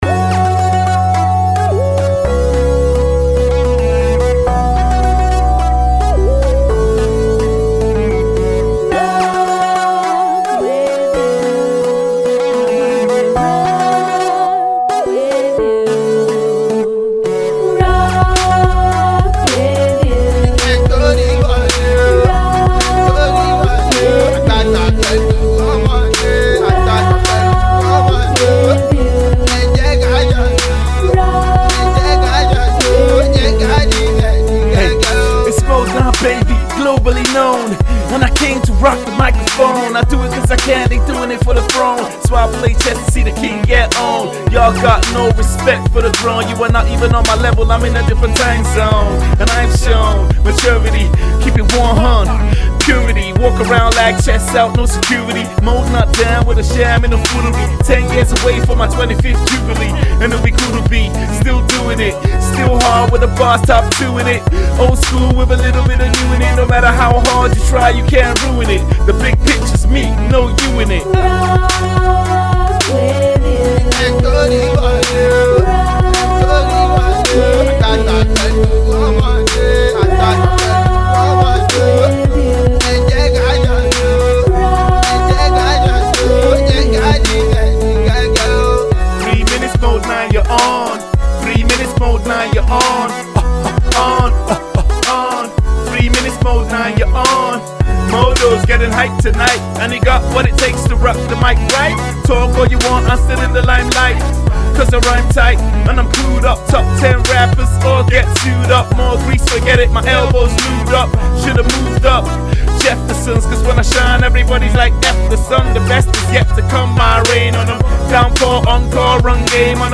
a Mellow Rap tune